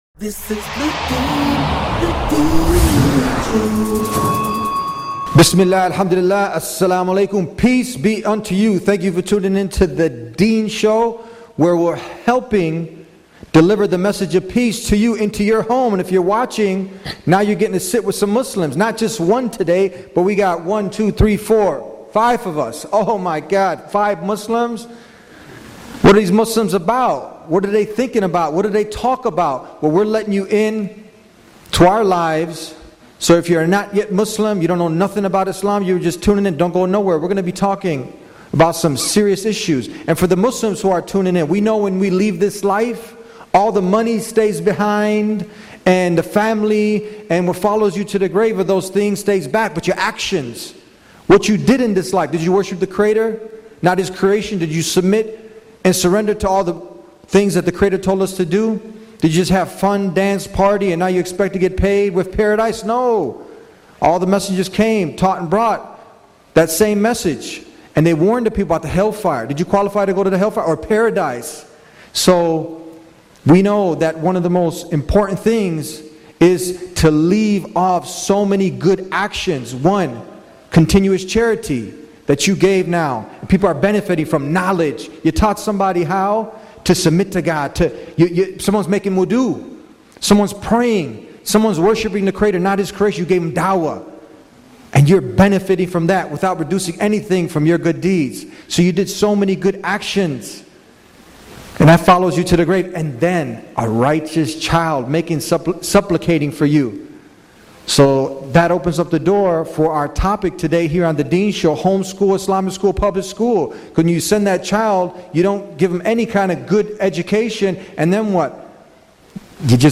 A panel of 4 Experts will be giving us the inside scoop of what is really happening in these institutions and what is the best direction to take in educating our children.